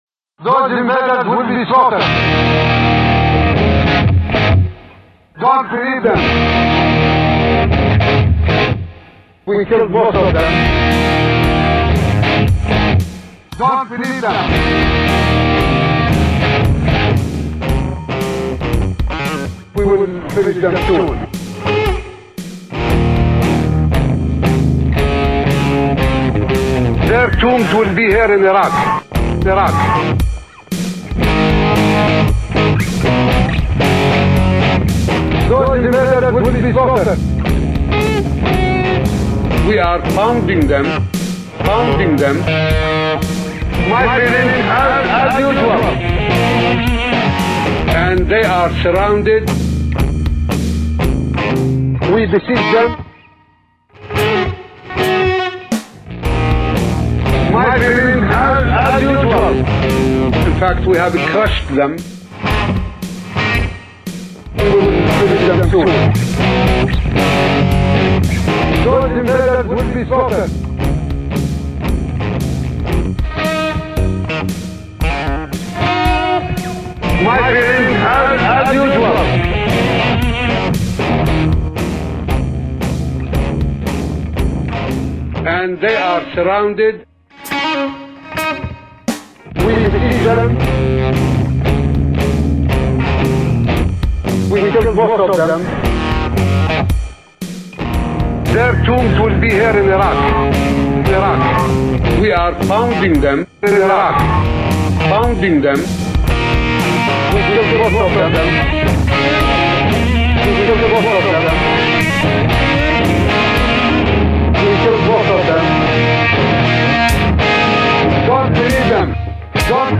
Tunes included for your pleasure, drop ins courtesy of Comical Ali, are "The Faker, The Seer,"